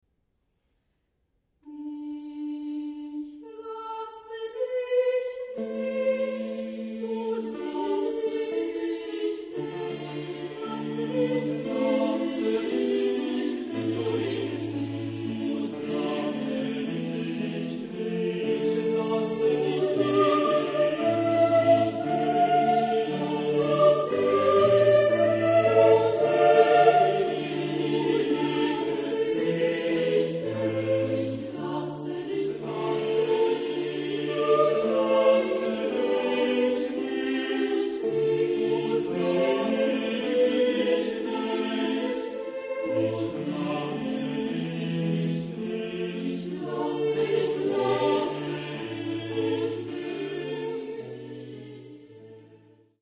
Genre-Stil-Form: geistlich ; Barock ; Motette
Chorgattung: SSATB  (5 gemischter Chor Stimmen )
Instrumentation: Continuo  (1 Instrumentalstimme(n))
Instrumente: Cembalo (1) oder Orgel (1)
Tonart(en): d-moll
von Dresdner Kammerchor gesungen unter der Leitung von Hans-Christoph Rademann